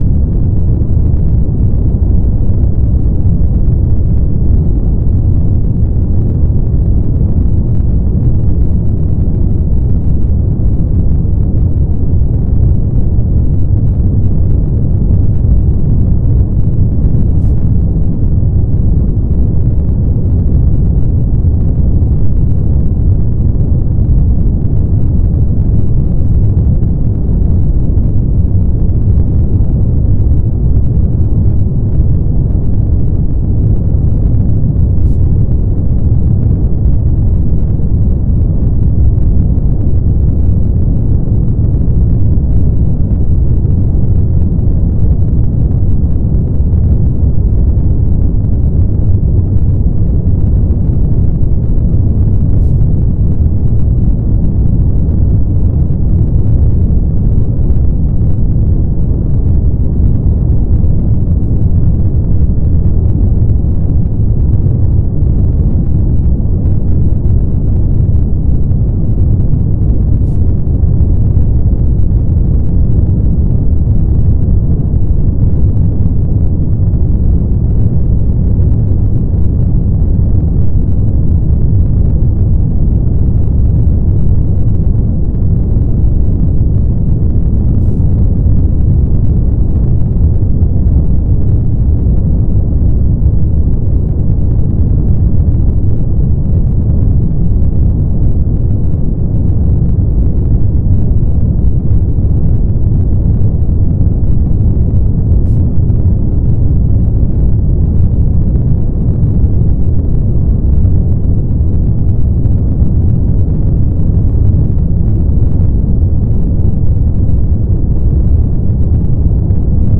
ssc_thruster4w.wav